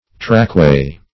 Meaning of trackway. trackway synonyms, pronunciation, spelling and more from Free Dictionary.
Trackway \Track"way`\, n.